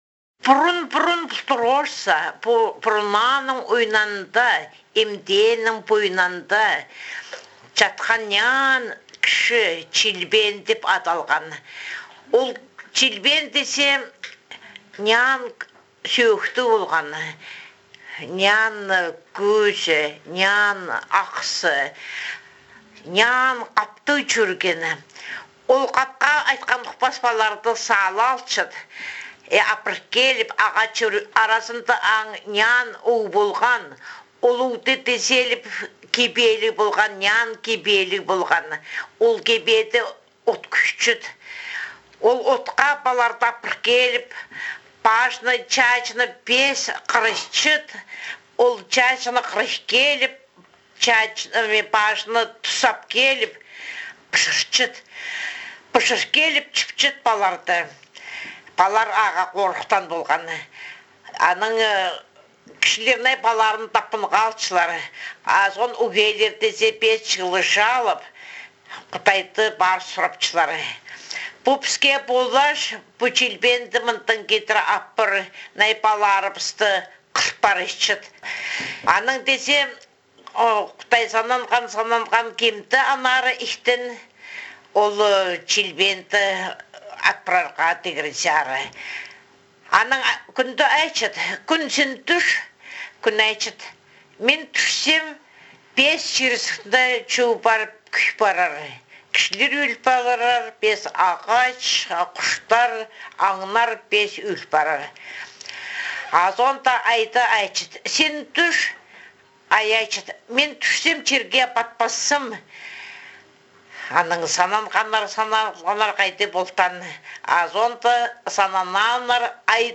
2. Аудиозаписи речи